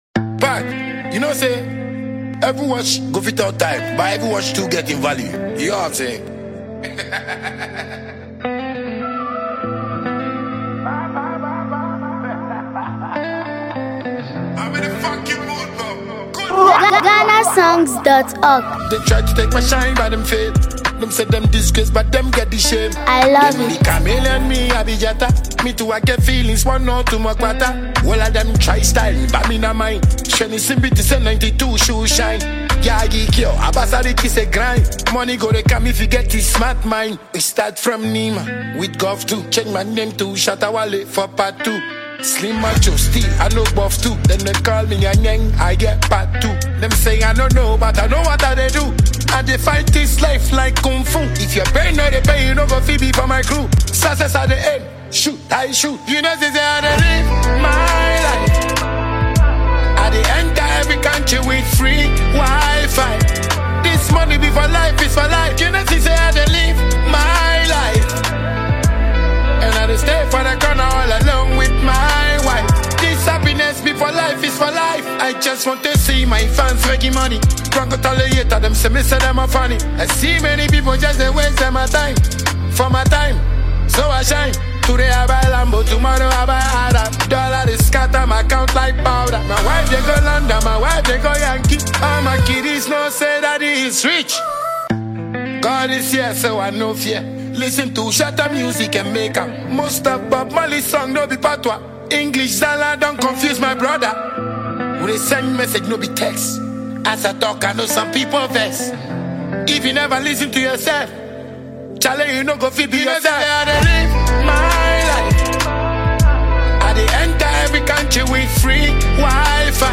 a bold and energetic track
rides on a simple but catchy beat